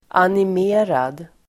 Ladda ner uttalet
animerad adjektiv, animated , lively Uttal: [anim'e:rad]